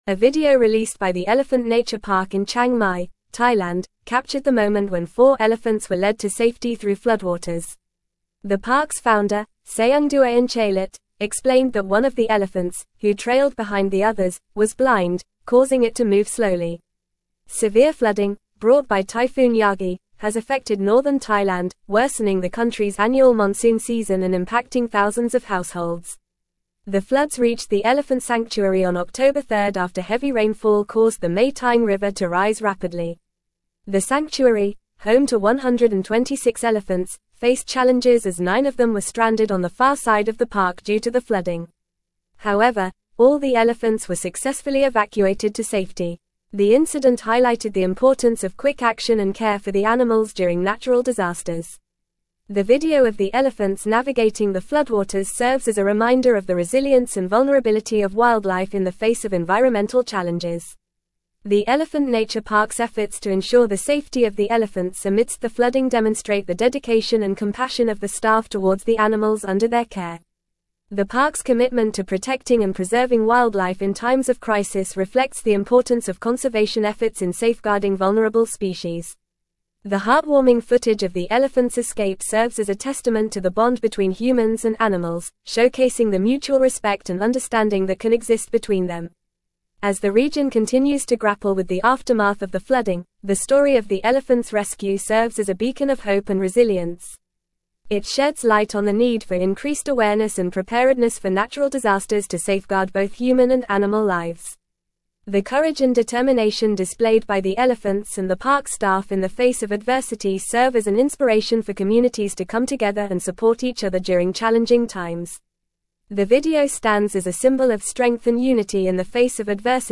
Fast
English-Newsroom-Advanced-FAST-Reading-Elephants-rescued-from-floodwaters-in-Thailand-sanctuary.mp3